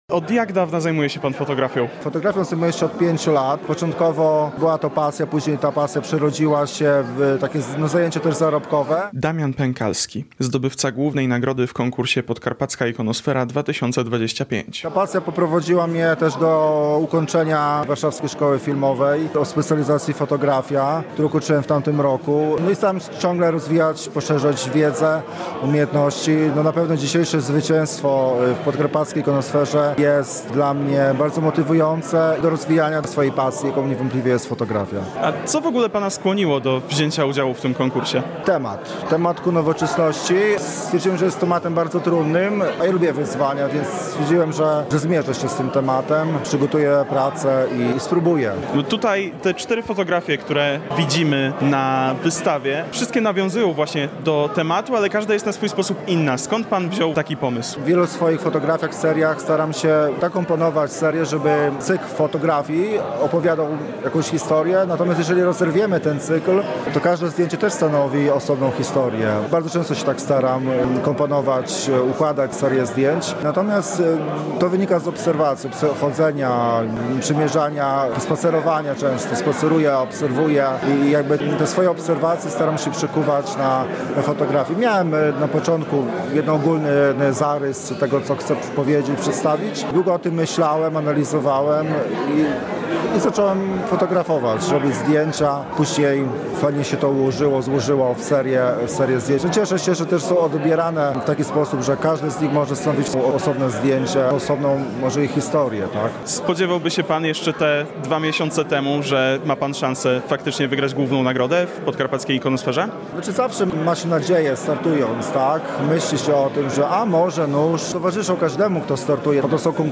Nagrody wręczono podczas piątkowej inauguracji wystawy pokonkursowej.